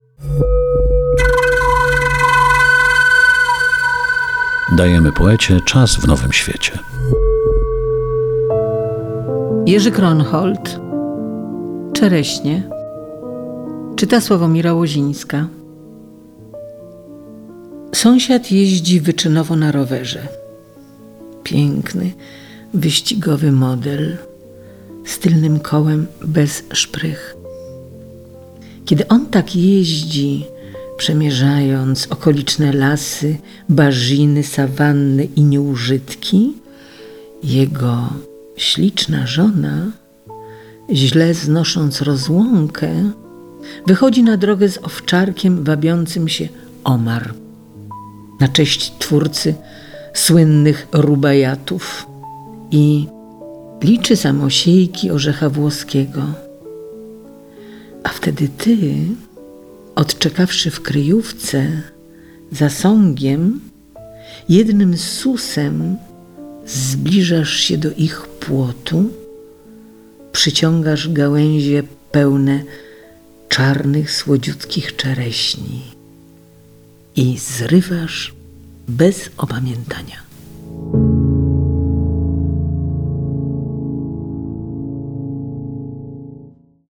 Kronhold "Czereśnie" - czyta Sławomira Łozińska.